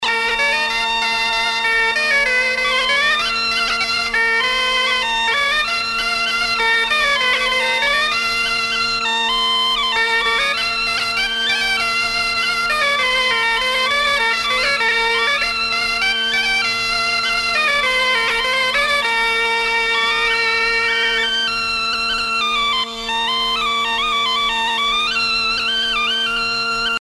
danse : an dro
Pièce musicale éditée